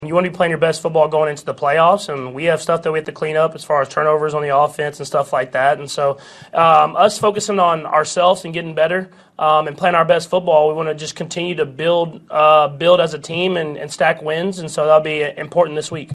Chiefs quarterback Patrick Mahomes says the focus remains on improving.
12-24-patrick-mahomes-focus-on-getting-better.mp3